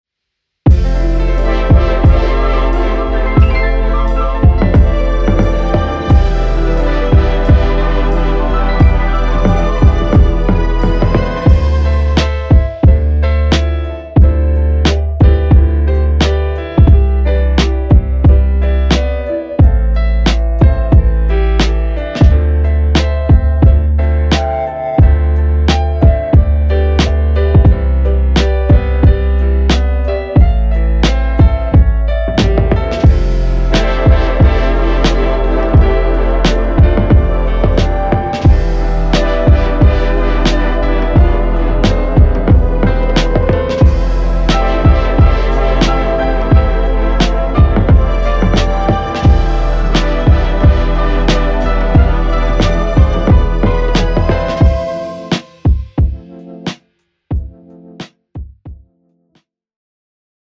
Teletone Audio Golden Age Grand是一款钢琴虚拟乐器插件，它可以让你体验到20世纪50年代和60年代钢琴独奏的黄金时代的音色。
- 它使用了一架雅马哈大钢琴的样本，这架钢琴经过了精心的老化处理，具有独特的个性和魅力。
- 它通过复古的麦克风、前置放大器和定制的复古控制台进行了录制，使你能够直接感受到黄金时代的声音。
- 磁带样本集是将样本录制到2英寸的模拟磁带上，然后将播放速度降低到27ips，使钢琴的声音更加温暖和自然。
- 数字样本集是将样本进行数字时间拉伸和降调一个小三度，使钢琴的声音更加梦幻和空灵。